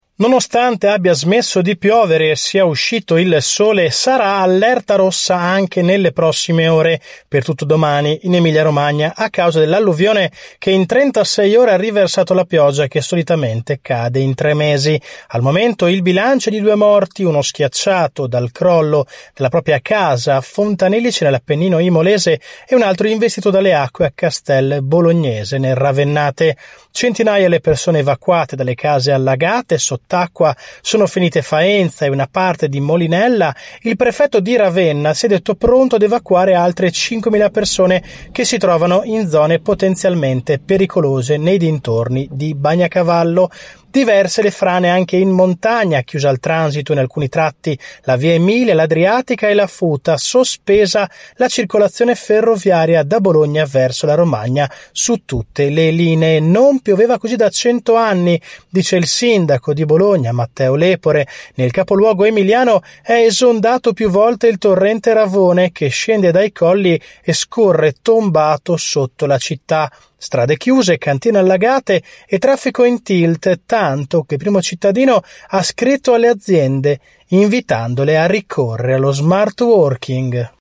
Il racconto della giornata di mercoledì 3 maggio 2023 con le notizie principali del giornale radio delle 19.30. Due morti e un disperso, oltre 400 persone evacuate e altre 5mila che rischiano nelle prossime ore di dover lasciare le loro case: sono le conseguenze del violento nubifragio che si è abbattuto tra ieri sera e questa mattina sull’Emilia Romagna.